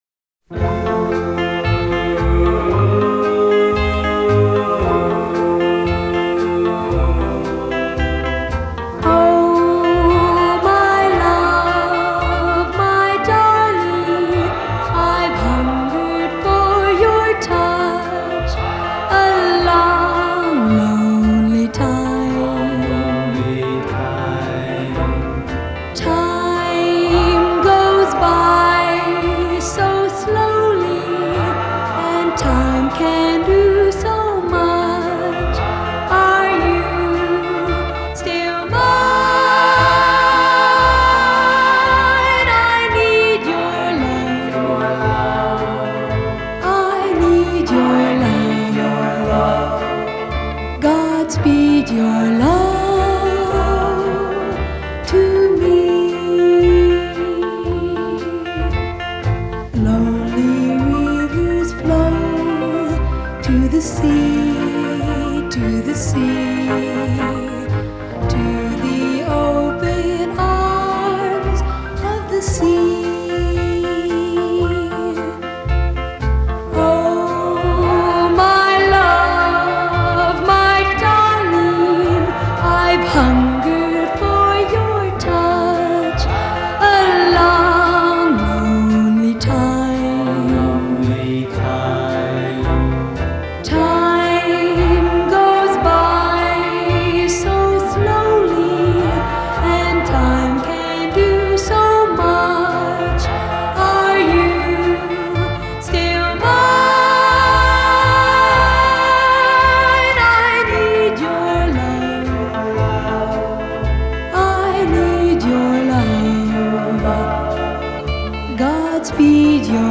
Жанр: Pop, Oldies, Doo-Woop
lead vocals